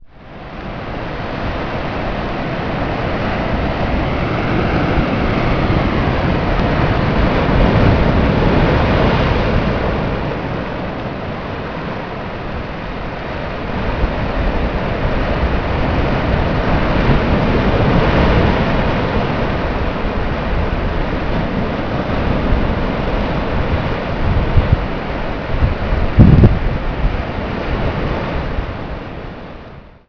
wav or ra] cliffs at mackenzie park cliffs (with audio) at mackenzie state park